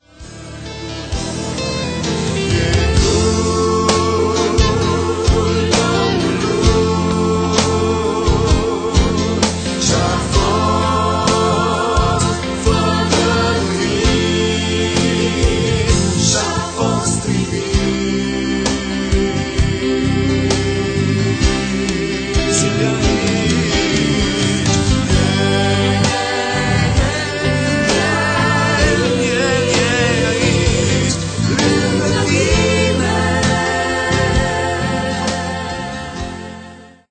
Un alt album de lauda si inchinare